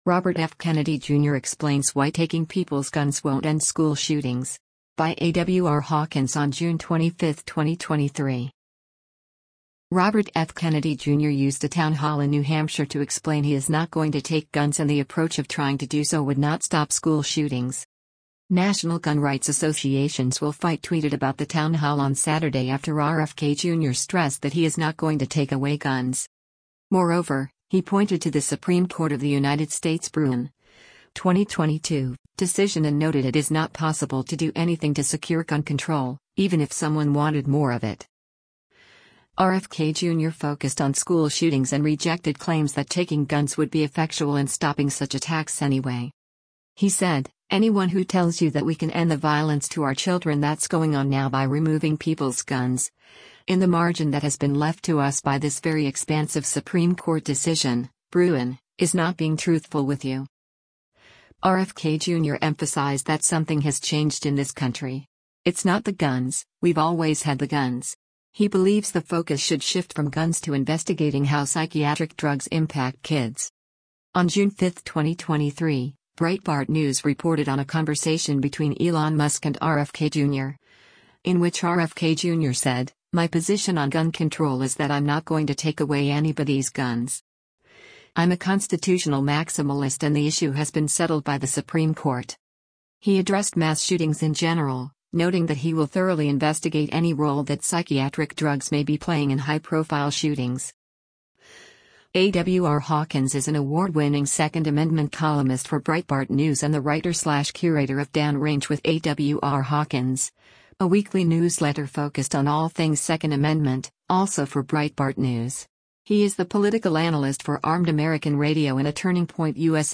Robert F. Kennedy Jr. used a townhall in New Hampshire to explain he is not going to take guns and the approach of trying to do so would not stop school shootings.